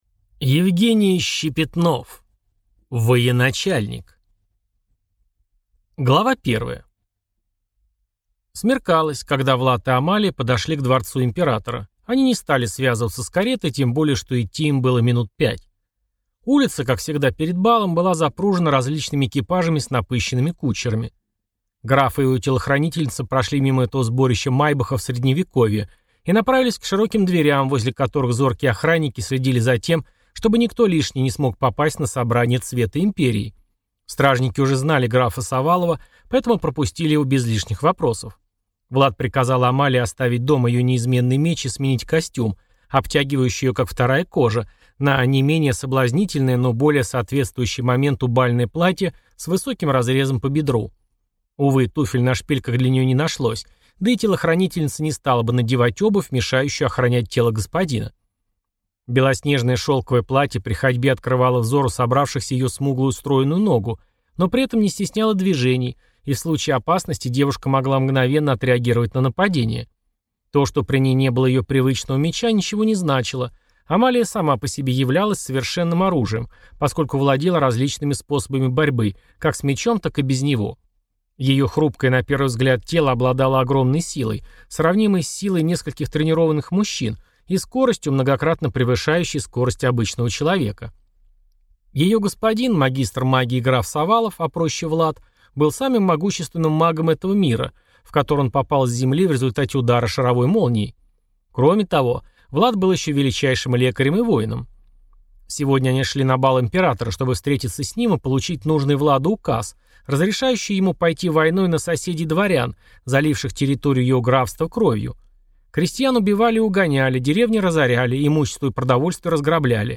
Аудиокнига Военачальник | Библиотека аудиокниг